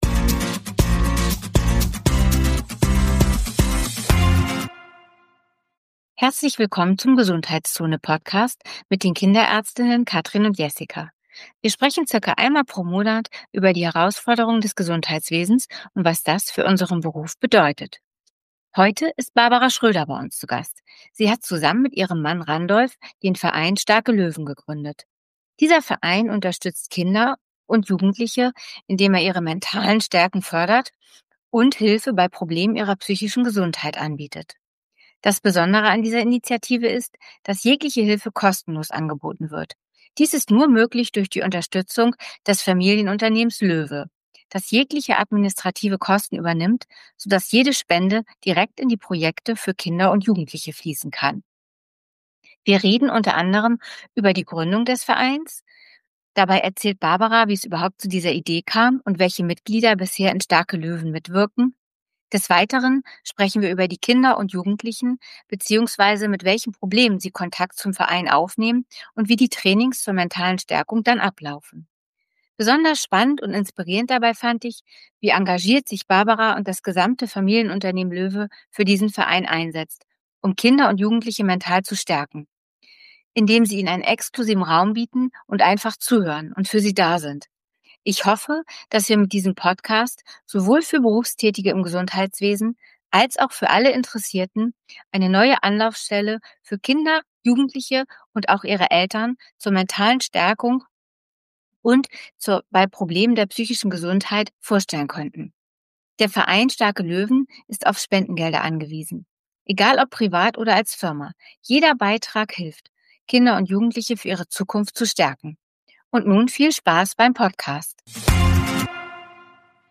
~ GESUNDHEITSZONE // zwei Kinderärzte über Probleme und Lösungen in ihrem Job Podcast